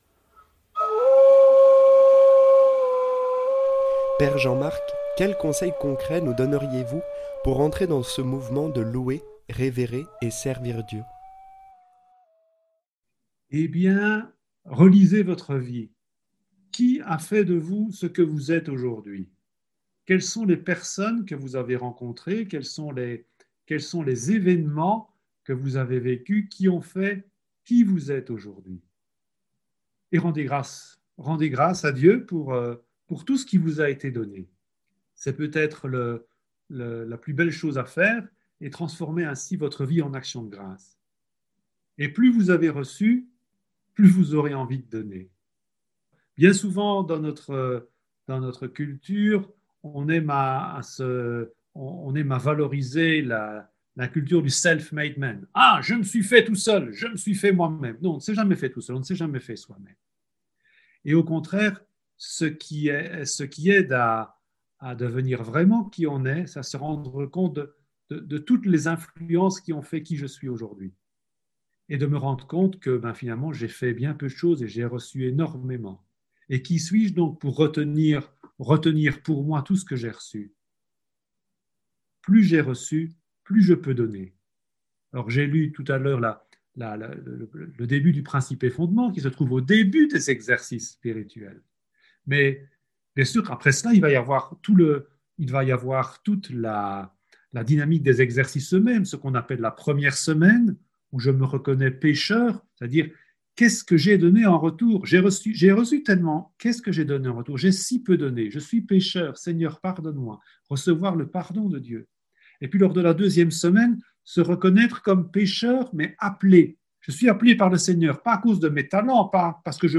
> Transcription de l’interview